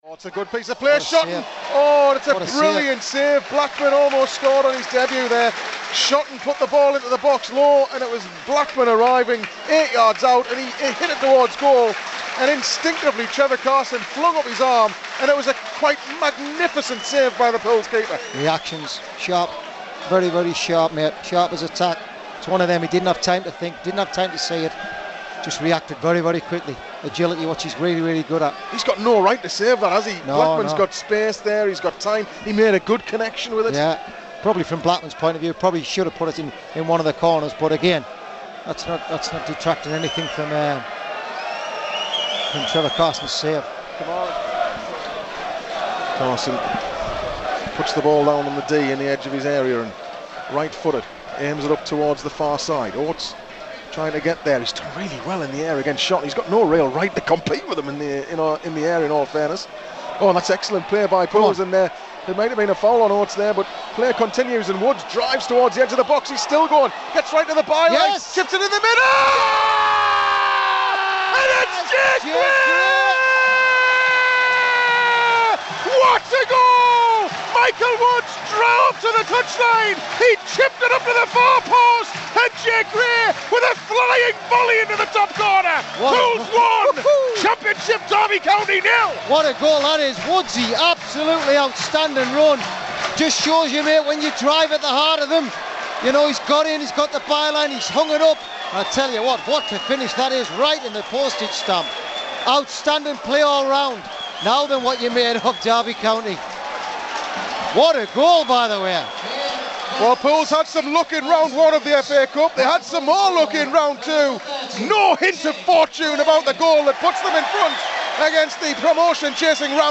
Listen back to all the key moments from Saturday's FA Cup tie as they sounded live on Pools PlayerHD.